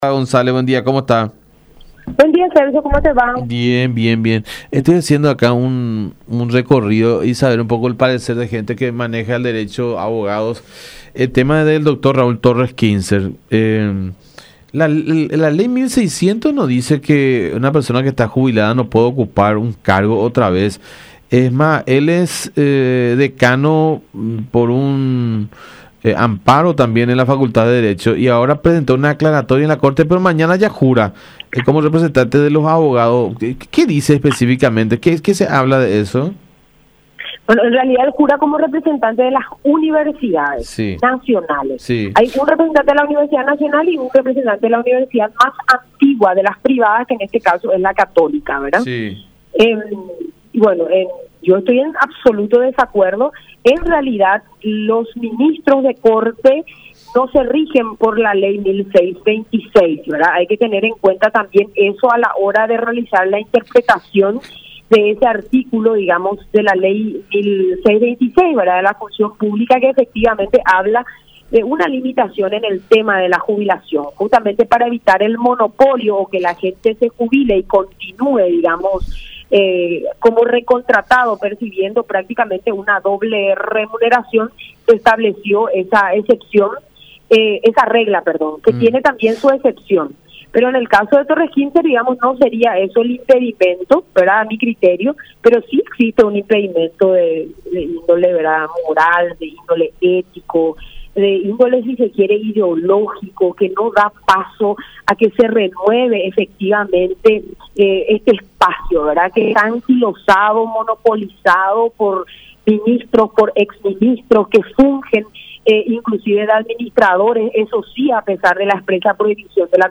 “No da paso a que se renueve ese espacio anquilosado y monopolizado por ministros y exministros que fungen de administradores”, repudió la legisladora en diálogo con La Unión.